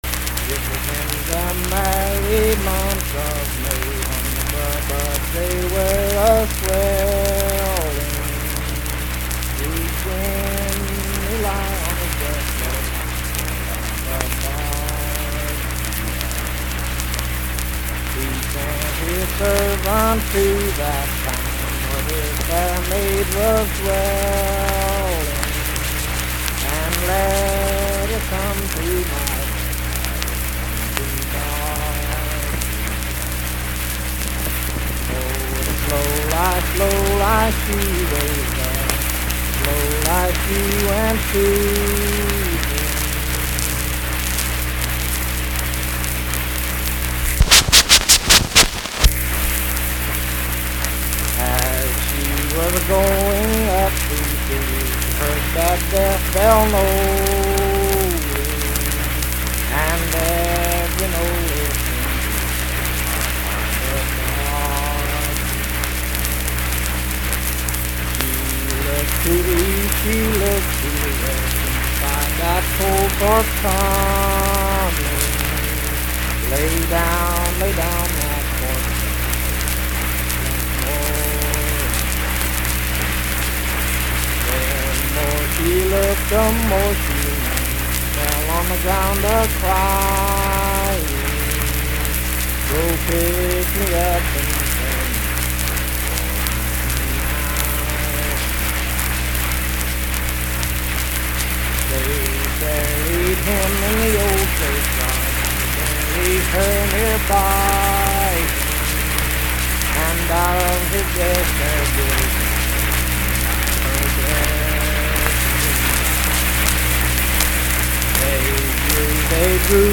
Unaccompanied vocal music
Voice (sung)
Saint Marys (W. Va.), Pleasants County (W. Va.)